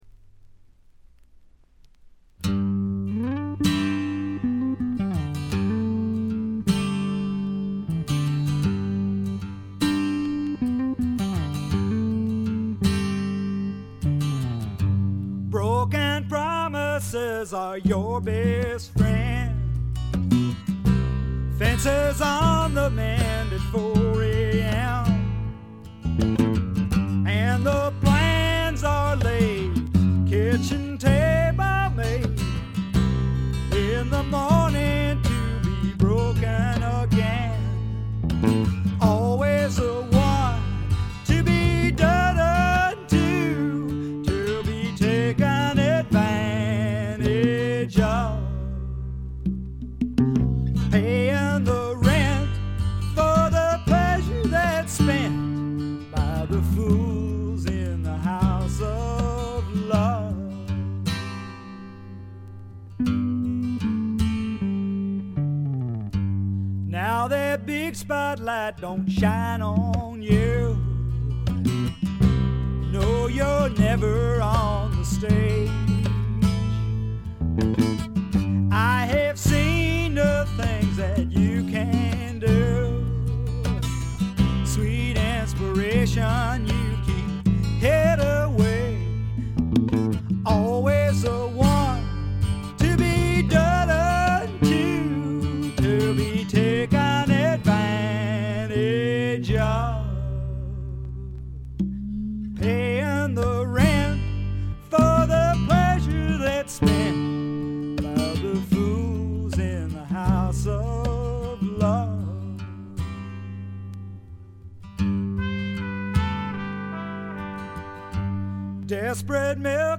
微細なバックグラウンドノイズがわずかに聴かれる程度。
いかにもカナダらしい清澄な空気感と薄味のルーツ系の味付けも心地よいですね。
試聴曲は現品からの取り込み音源です。
Guitar, Mandolin, Autoharp, Vocals
Harmonica, Trumpet, Euphonium
Drums, Congas, Percussion